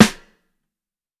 Sucker Snare.wav